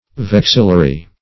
Vexillar \Vex"il*lar\, Vexillary \Vex"il*la*ry\, [Cf. F.